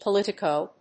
発音記号
• / pəlíṭɪkòʊ(米国英語)
• / pəlíṭɪk`əʊ(英国英語)
politico.mp3